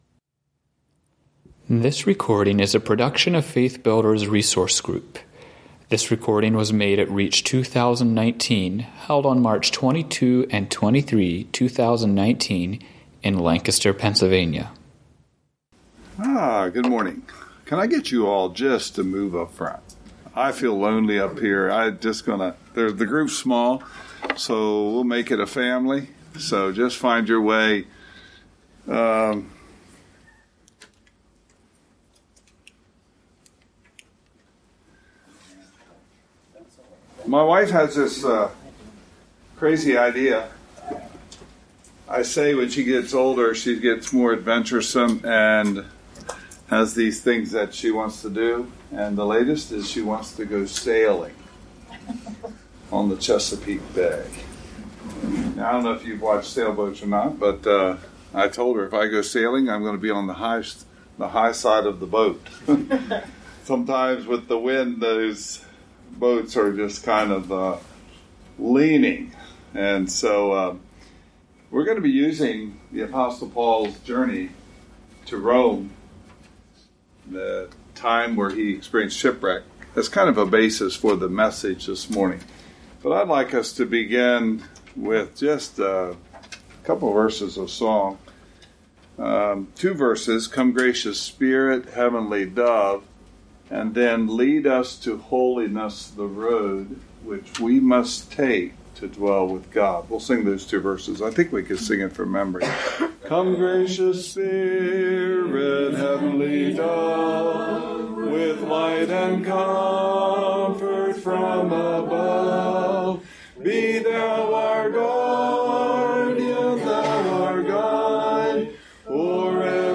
Home » Lectures » Becoming a Vessel of Honor